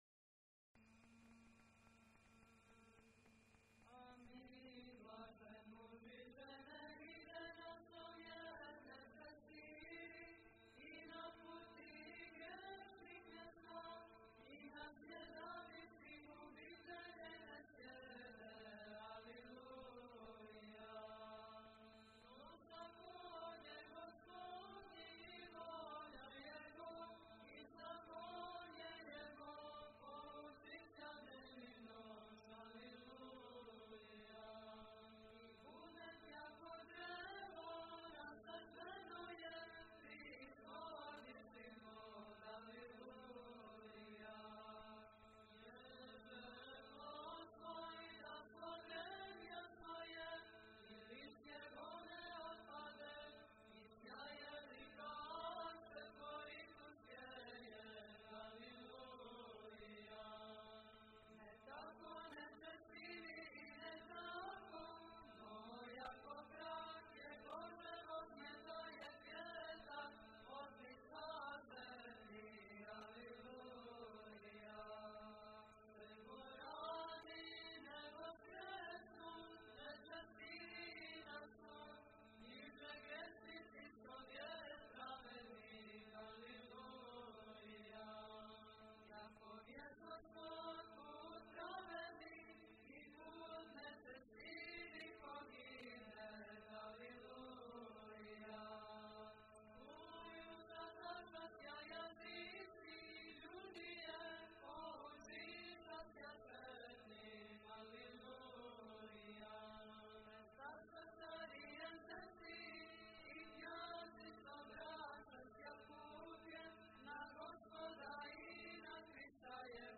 Појање из манастира Жича
02-Blessed-Is-The-Man-Tone-4.mp3